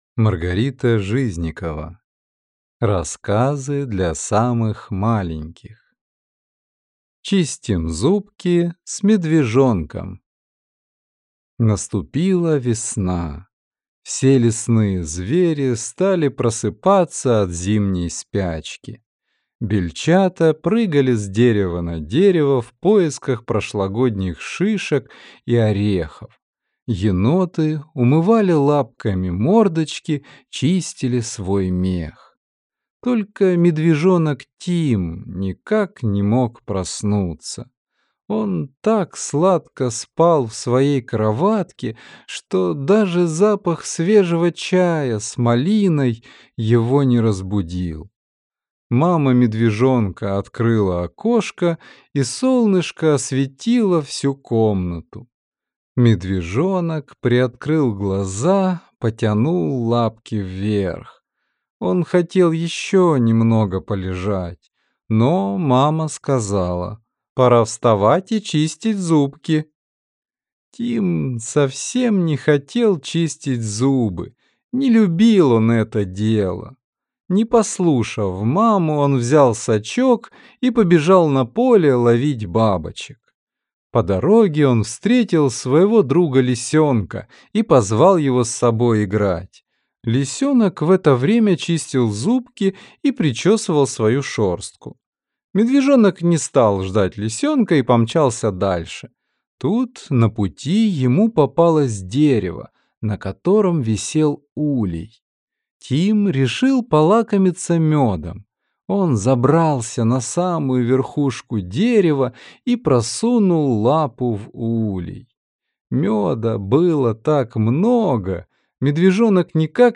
Аудиокнига Рассказы для самых маленьких | Библиотека аудиокниг